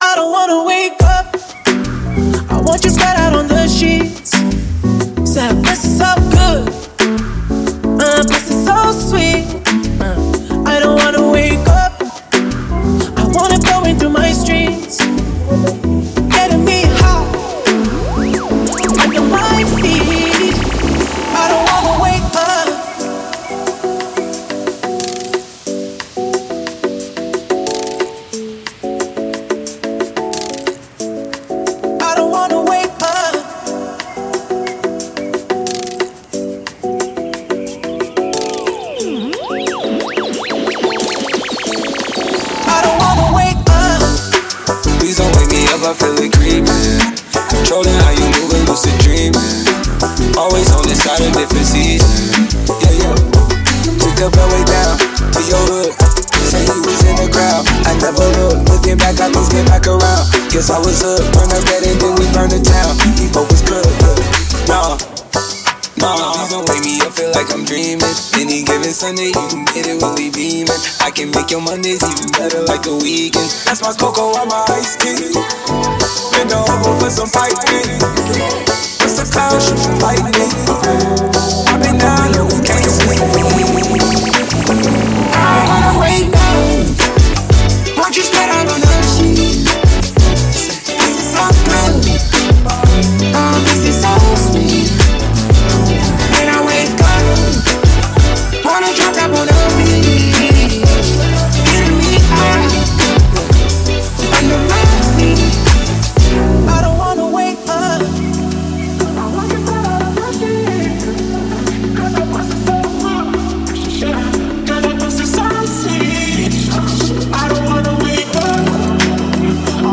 Genre: Rap / hip hop